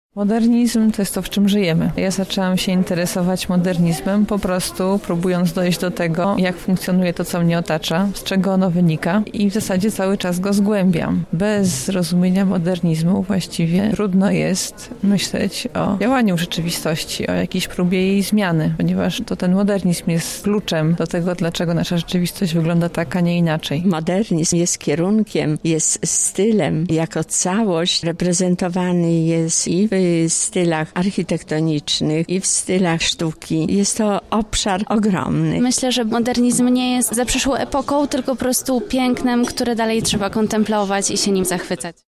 O to, czym dla zwykłego odbiorcy jest modernizm pytała nasza reporterka.